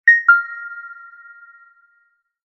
جلوه های صوتی
دانلود آهنگ اعلان پیام 2 از افکت صوتی اشیاء